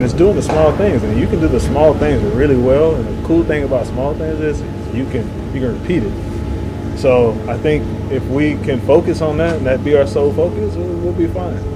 Designated hitter Andrew McCutchen says the Pirates will turn it around by concentrating on doing the little things right.